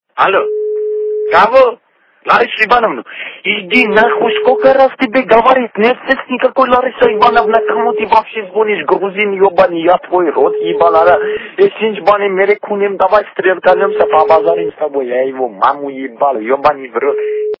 » Звуки » Люди фразы » Альо, альо! - Это Лариса Ивановна?